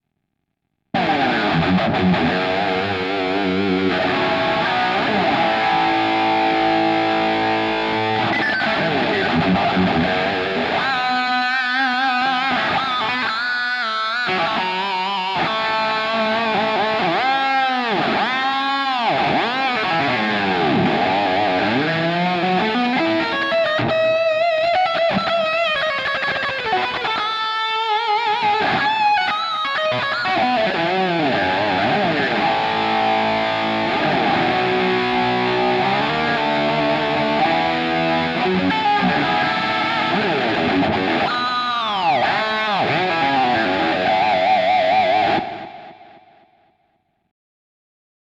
[edit] a small Kemper clip, Just a 1977 JMP profile into a V30 using my JJ1 guitar with JB pickup.